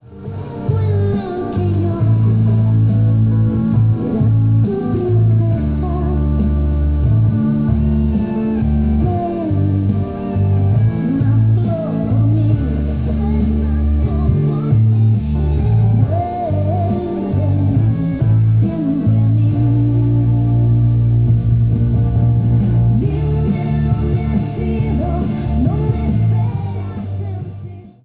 Tema musical